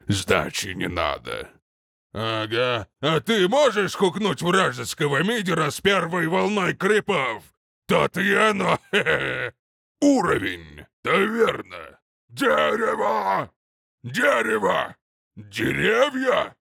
Муж, Пародия(Пудж (Dota 2))